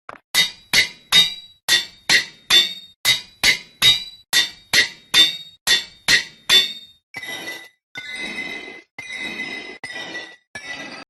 Звуки ударов по наковальне звонки и мелодичны.
Звуки наковальни и точильного камня
Звуки-наковальни-и-точильного-камня-minecraft-asmr-respect-shorts-256-kbps.mp3